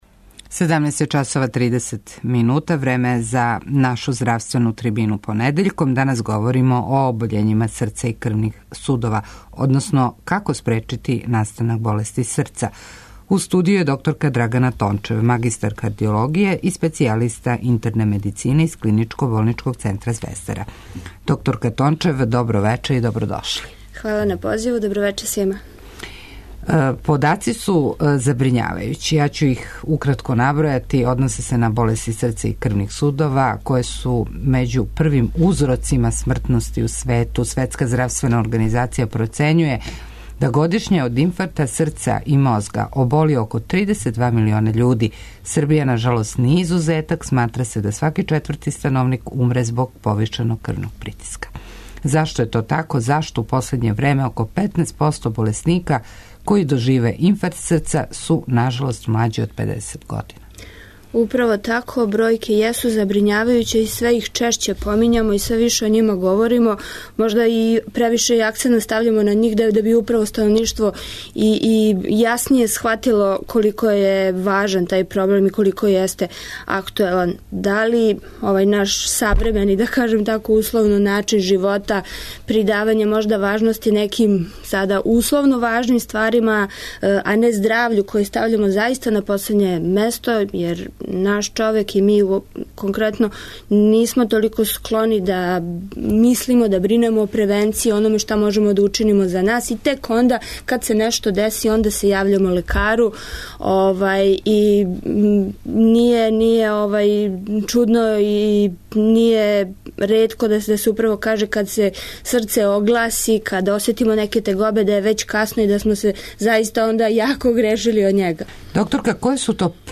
У здравственој трибини магазина 'У средишту пажње' говоримо о обољењима срца и крвних судова. Кардиоваскуларна обољења узрок су преко 50 одсто смртних случајева и у порасту су због неадекватног стила живота.